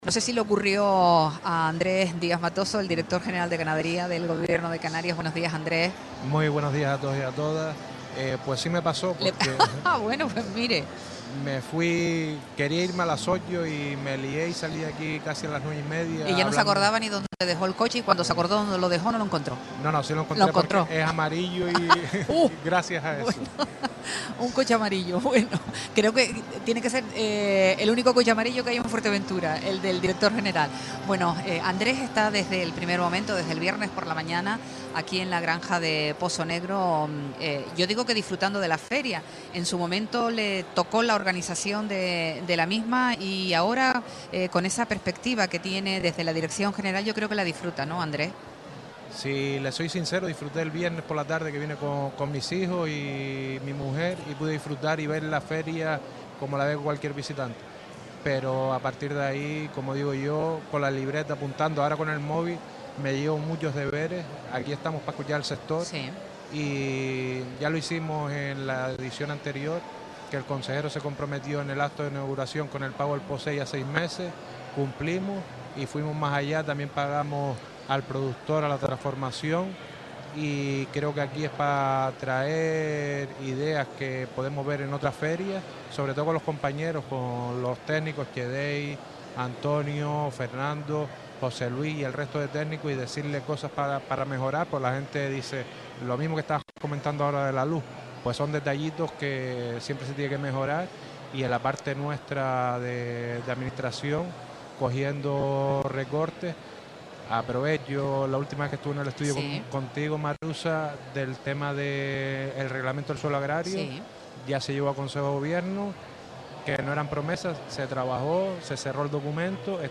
Este domingo, 6 de abril, en el especial FEAGA de Radio Sintonía en la Granja Experimental de Pozo Negro, Andrés Díaz Matoso expuso las distintas acciones que la Consejería del Sector Primario, dirigida por Narvay Quintero, está impulsando para facilitar la incorporación de nuevos profesionales al sector y garantizar mejoras para ganaderos y agricultores.
Entrevistas